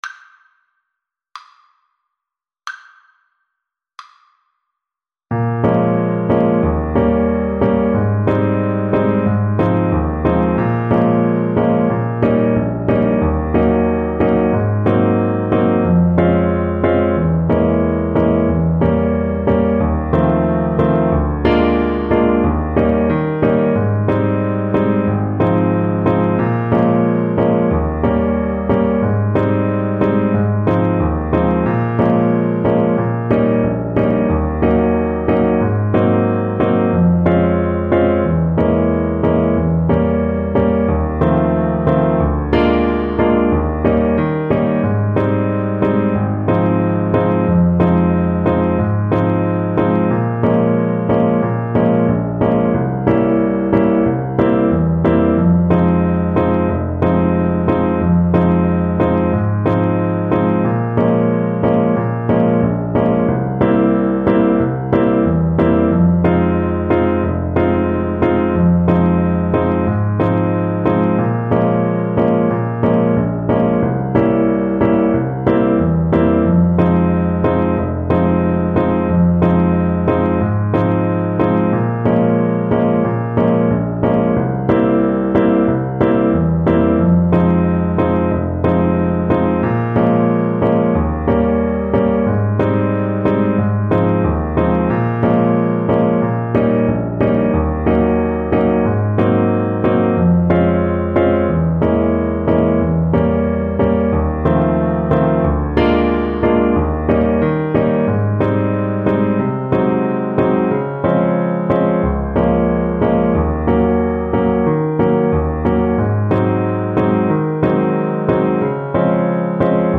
Alto Saxophone
2/4 (View more 2/4 Music)
World (View more World Saxophone Music)
Brazilian Choro for Alto Sax